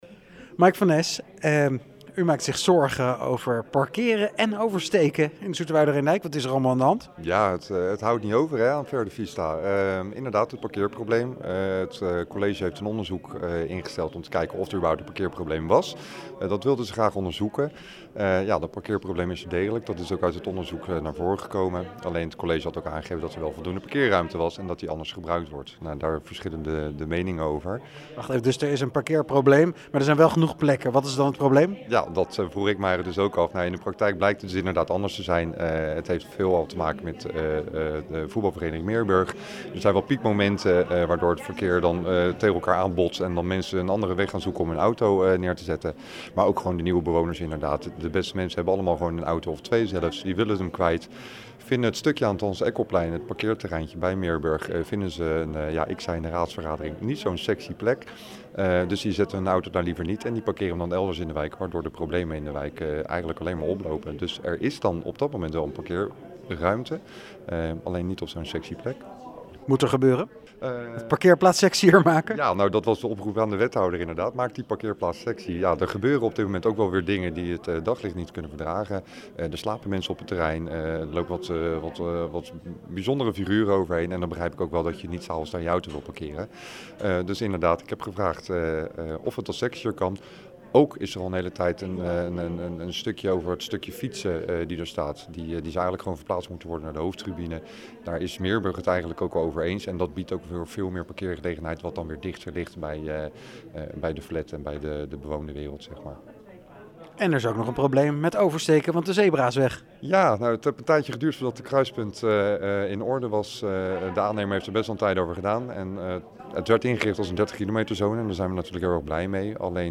PZ-raadslid Mike van Es over de parkeerproblemen in Verde Vista.